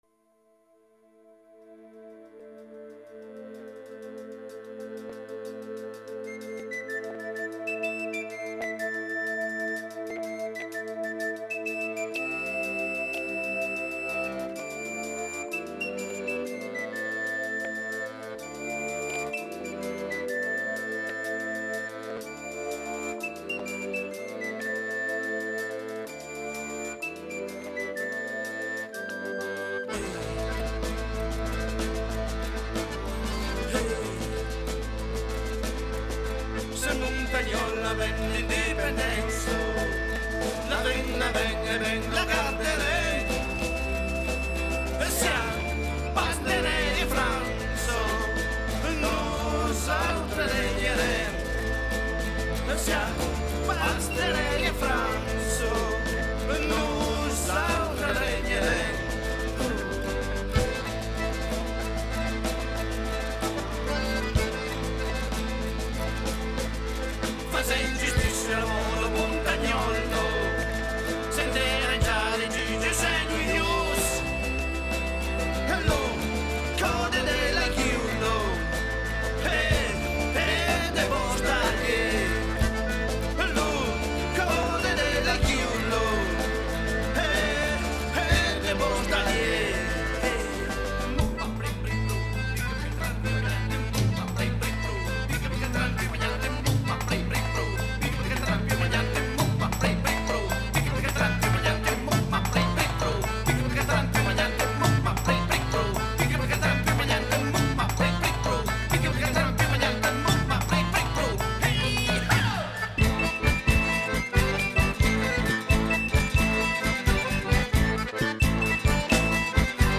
Sen mountanhòl (песня диких провансальских горцев).